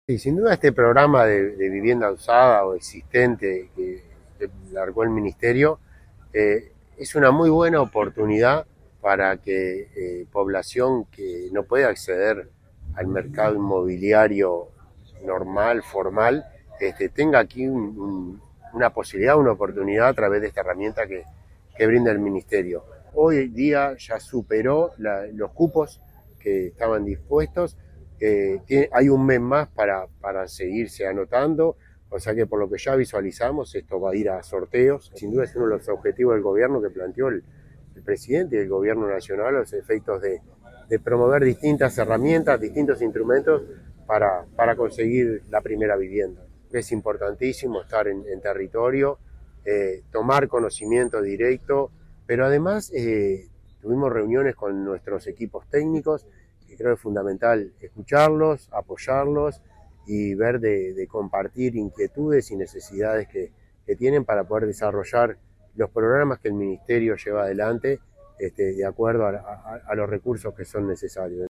Declaraciones del director nacional de Vivienda, Milton Machado